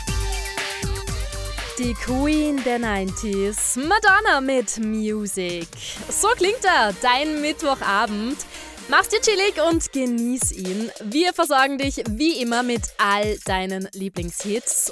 Radio Eurodance X-Press Moderation